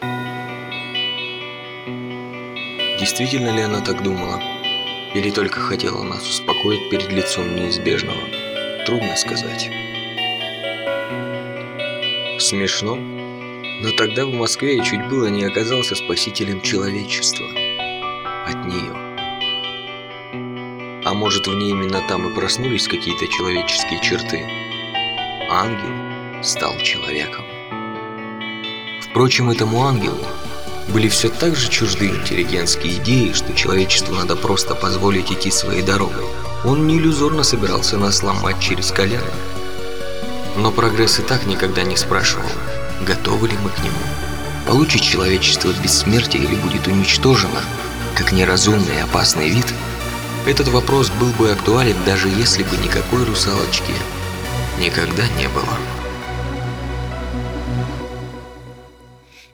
Техно-опера